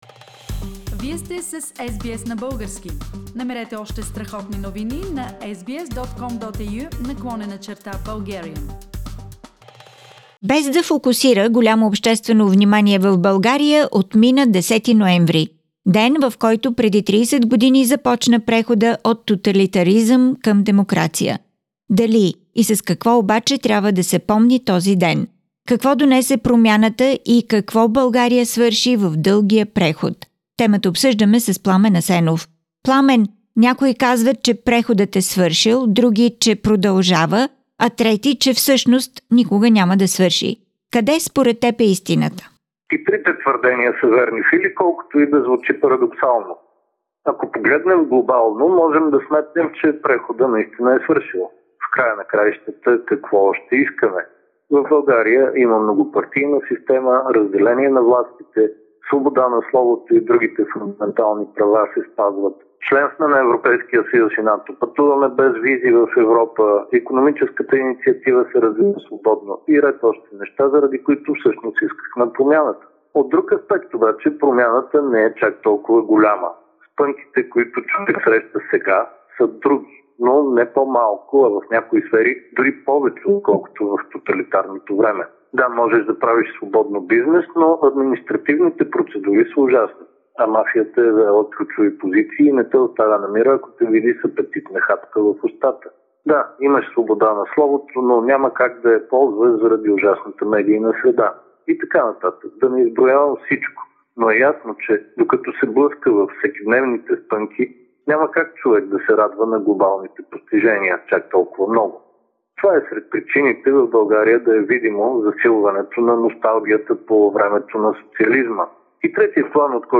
Political Analysis